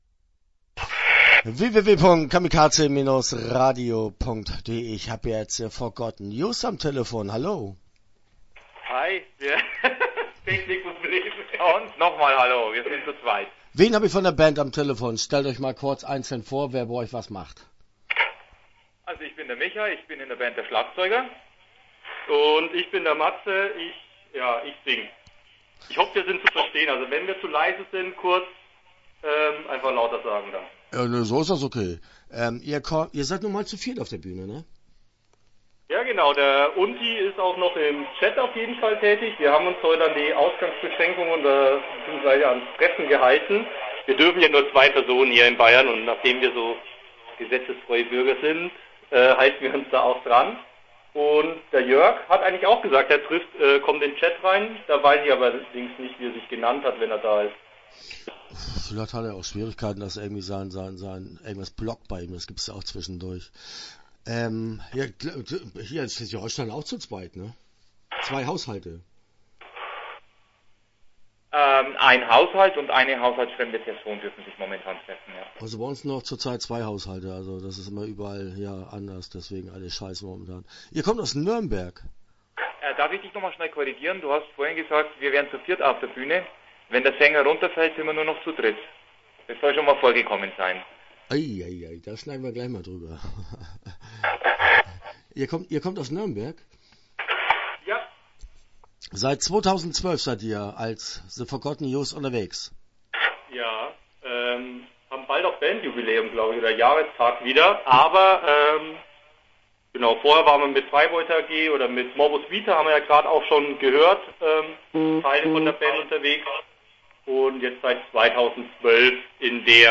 Start » Interviews » The Forgotten Youth